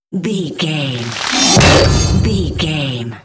Dramatic horror metal hit
Sound Effects
Atonal
heavy
intense
dark
aggressive